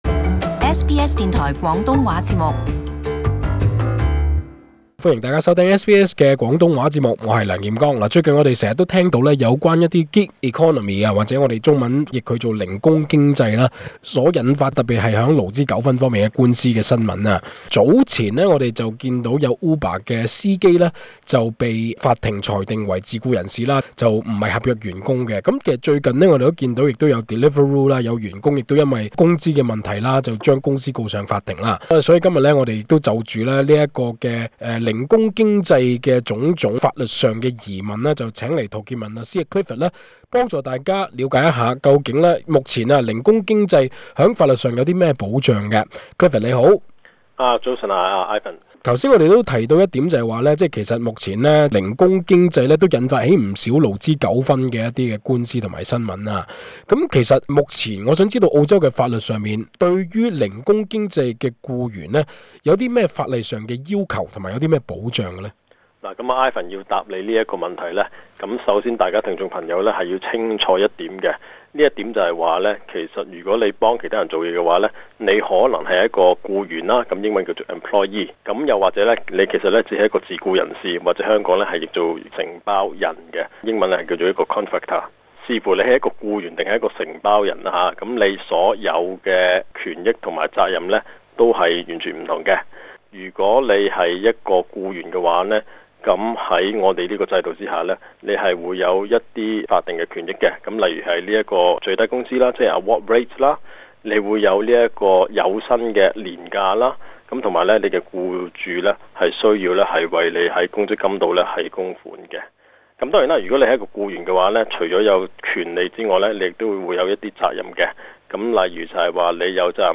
【社區專訪】處理零工經濟爭議令本地法庭無所適從？